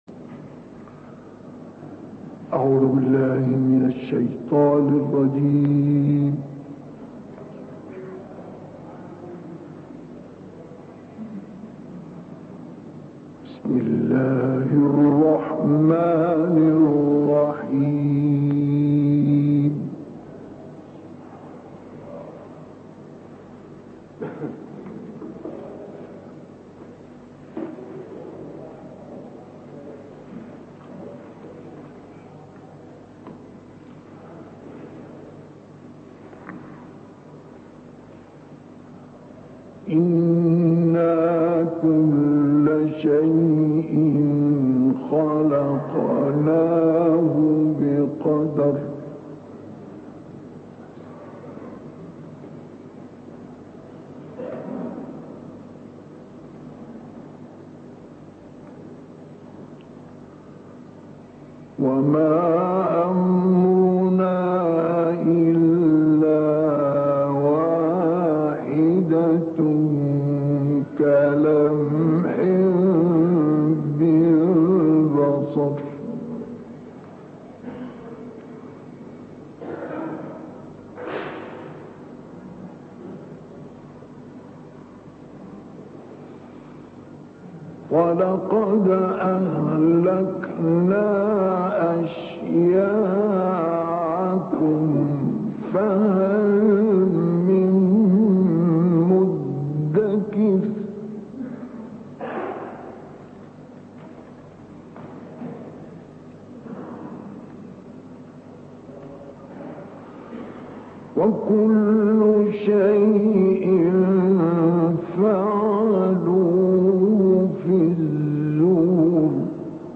আযহার জামে মসজিদে ক্বারি মোস্তাফা ইসলামইলের তিলাওয়াত
আন্তর্জাতিক ডেস্ক: মিশরের আযহার জামে মসজিদে সেদেশের প্রসিদ্ধ ক্বারি মোস্তাফা ইসলামইলের সুললিত কণ্ঠে কুরআন তিলাওয়াতের অডিও ফাইল সামাজিক নেটওয়ার্কে প্রকাশিত হয়েছে।
বার্তা সংস্থা ইকনা: বিশ্ববিখ্যাত ক্বারি মোস্তাফা ইসলামইল ১৯৭০ সালে মিশরের আযহার জামে মসজিদে জুমার নামাজের পরে সূরা কামারের ৪৯ থেকে ৫৫ নম্বর আয়াত এবং সূরা আল-রহমানের ১ থেকে ৩২ নম্বর আয়াত পর্যন্ত তিলাওয়াত করেছেন।